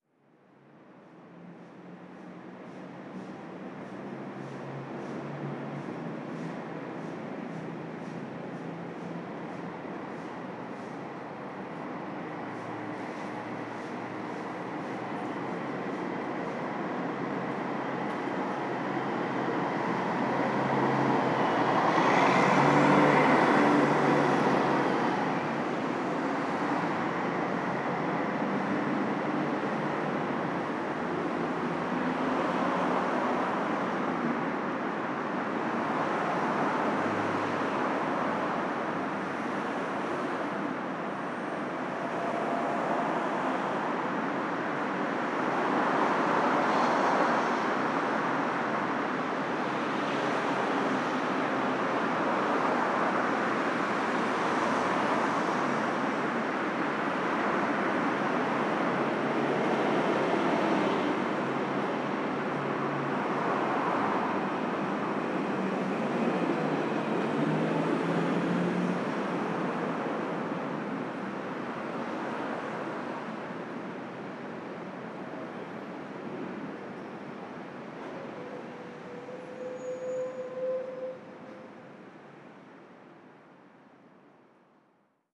Ambiente de la Gran Vía, Madrid
tráfico acelerar ambiente auto automóvil barullo calle circulación ciudad coche
Sonidos: Transportes Sonidos: Ciudad